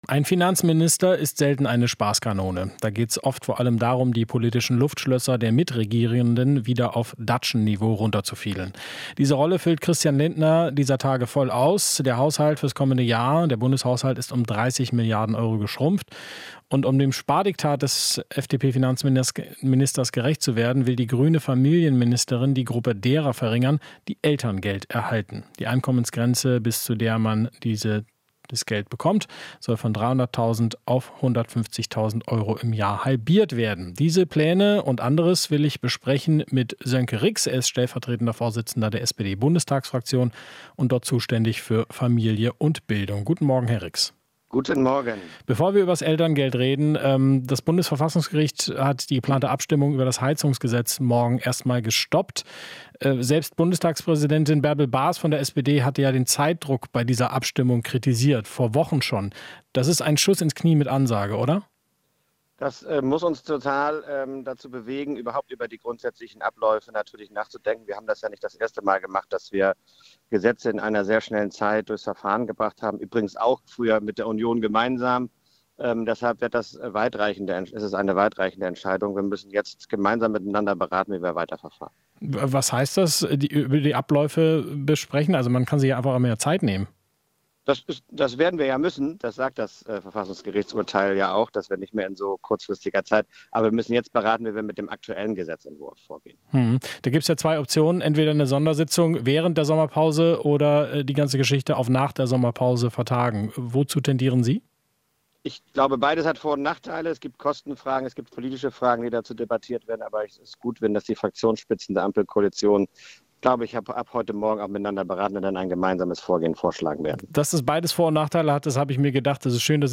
Interview - Rix (SPD): Höhere Steuern für Vermögen könnten Elterngeldkürzungen verhindern